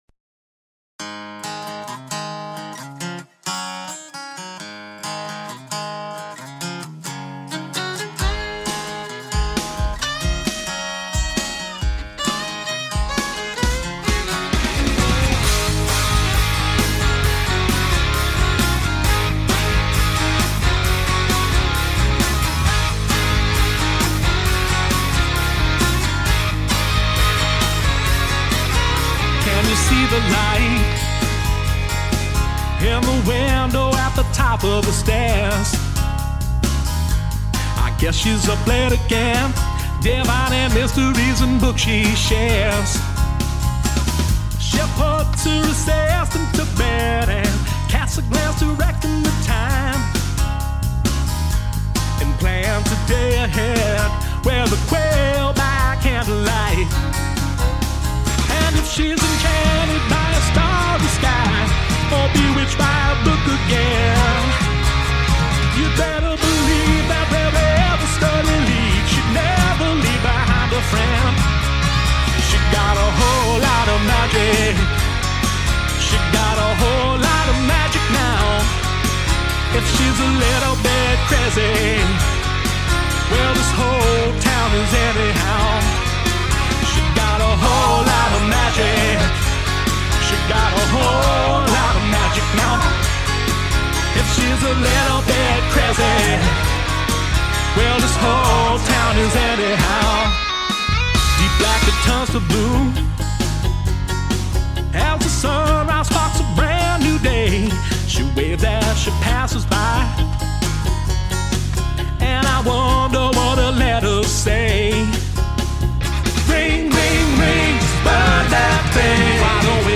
Violin